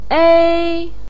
ēi hey (used to draw attention)